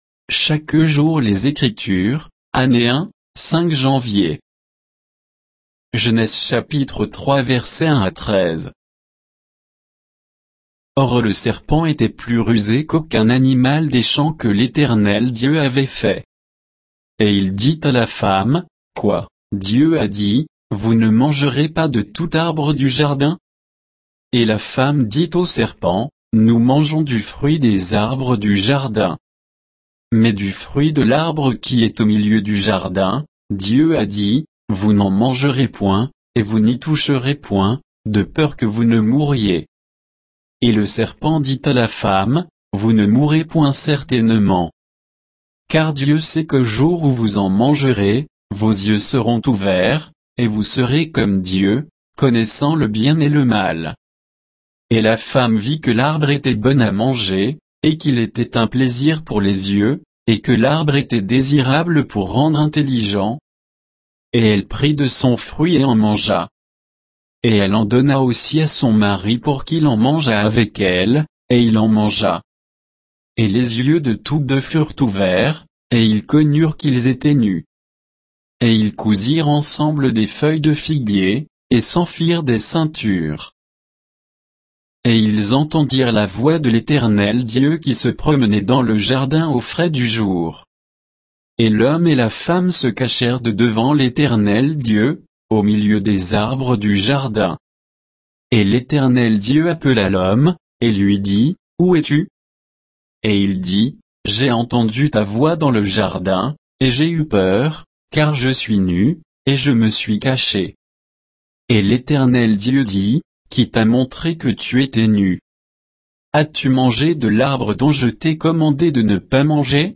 Méditation quoditienne de Chaque jour les Écritures sur Genèse 3, 1 à 13